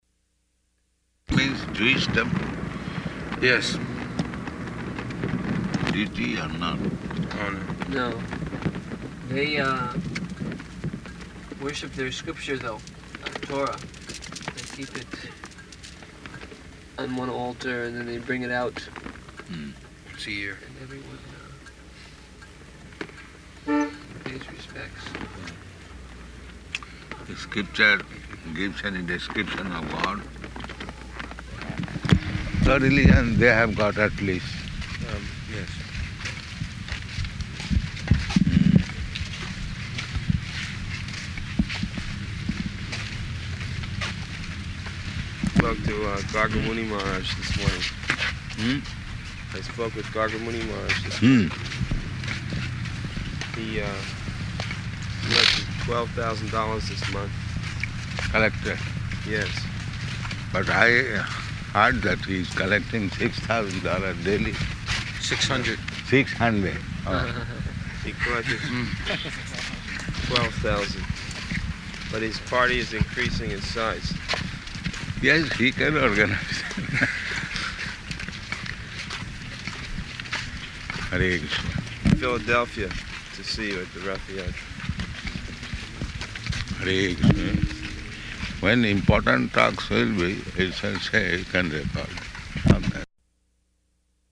Morning Walk
Type: Walk
Location: Chicago